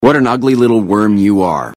achooboohoo
achooboohoo.mp3